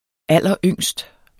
Udtale [ ˈalˀʌˈøŋˀsd ]